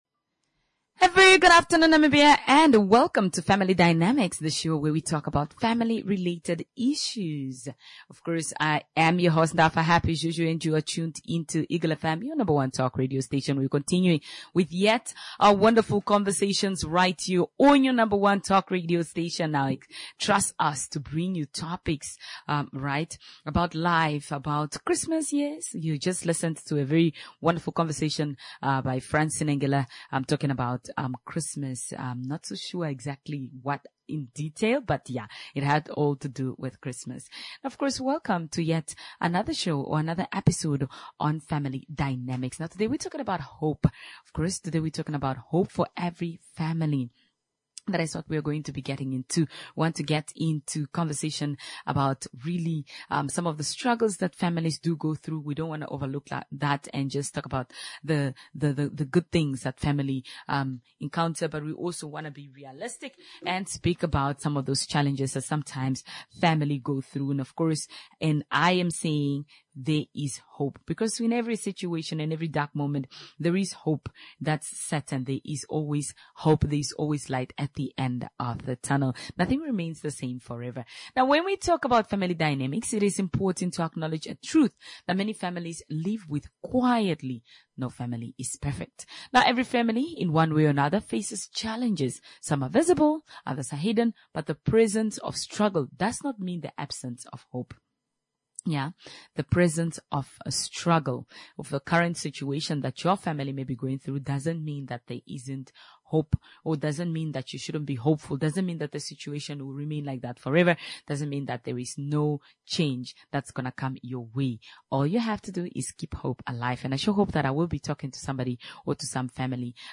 A discussion held in regards to challenges that families often face, financial hardship, a troubled child, illness, Grief and other aspects that affect a family. In all such situations – hope is still on the horizon, that requires our change of attitude.